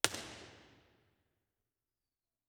A-format recordings were decoded to B-format, which were decoded to XY stereo.
Room Impulse Responses
IR_TP2_Stereo.wav